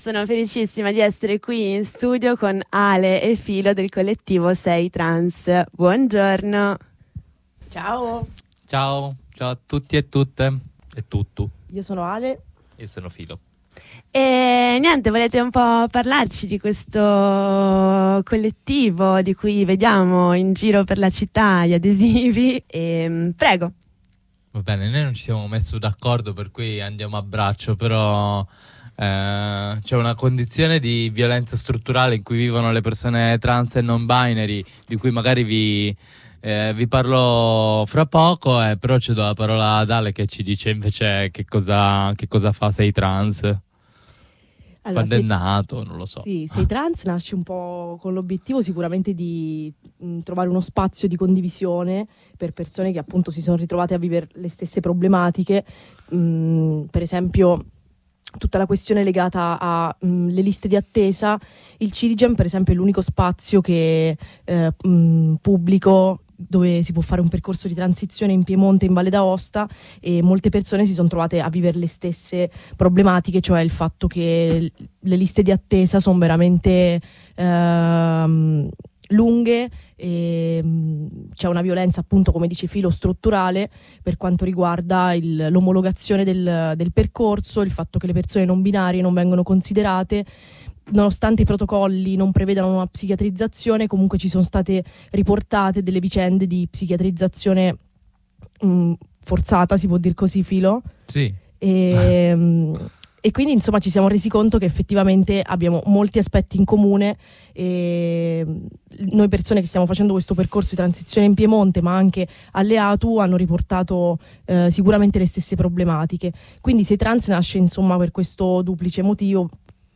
Una chiacchierata con due compagn* del collettivo Sei trans*, gruppo informale di persone trans^, non binarie e alleat* sulle problematiche legate ai percorsi di transizione al CIDIGeM di Torino, l’unico centro ospedaliero pubblico che serve l’utenza trans e non-binary di Piemonte e Val d’Aosta.